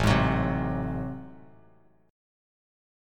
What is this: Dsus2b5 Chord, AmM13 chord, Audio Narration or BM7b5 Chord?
AmM13 chord